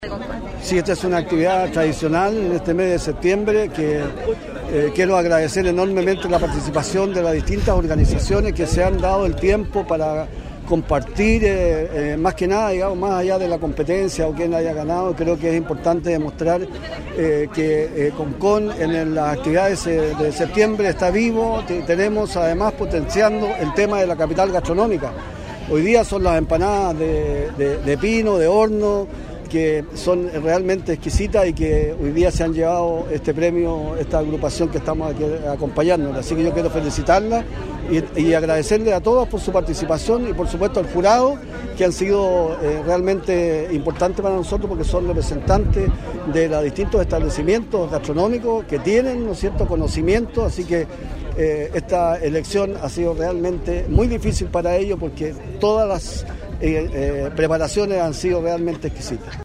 El alcalde Óscar Sumonte, agradeció la presencia de las distintas agrupaciones de la comuna que potencian el título de la comuna como la Capital Gastronómica de Chile, además de agradecer al jurado que son representantes de locales tradicionales de Concón (…) hay muchas actividades en este mes de las Fiestas Patrias. Felicito a los ganadores de este concurso por su preparación y triunfo en una elección muy difícil”.
ALCALDE-SUMONTE-X-CONCURSO-DE-EMPANADAS-01-.mp3